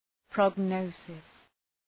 Προφορά
{prɒg’nəʋsıs} (Ουσιαστικό) ● πρόγνωση